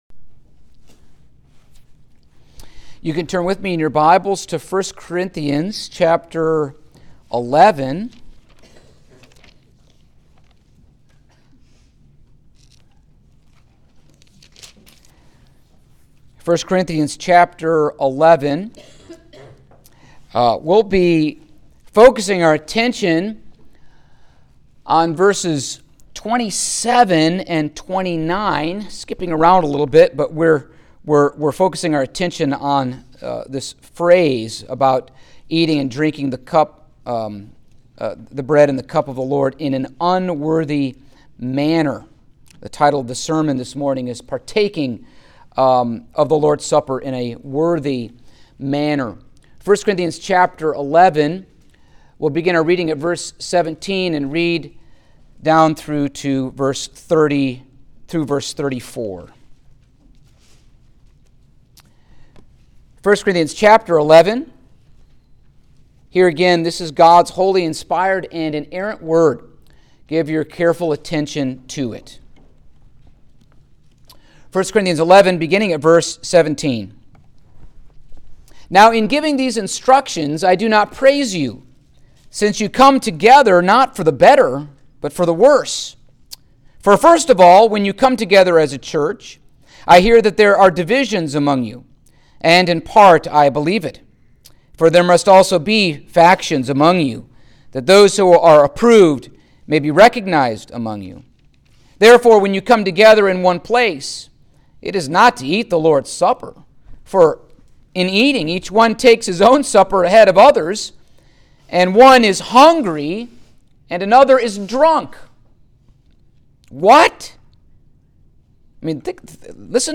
Passage: 1 Corinthians 11:27, 29 Service Type: Sunday Morning